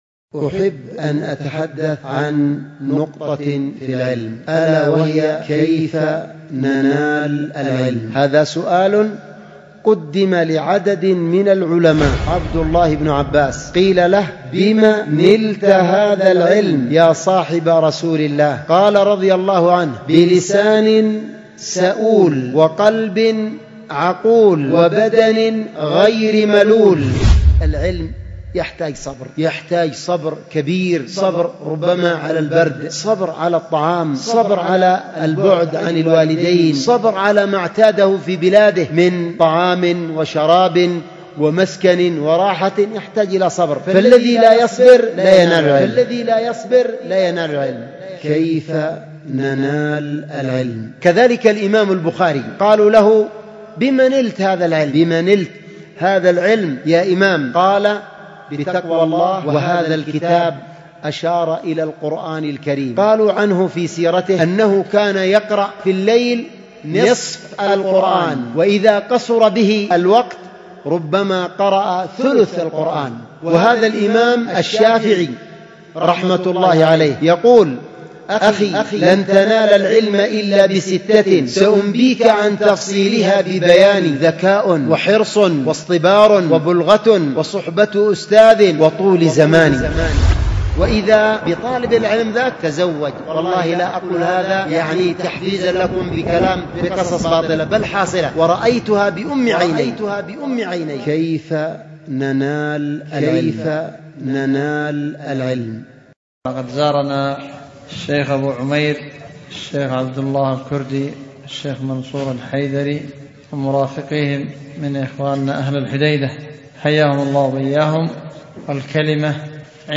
محاضرة: كيف ننال العلم
ألقيت بدار الحديث بمسجد السلف الصالح بذمار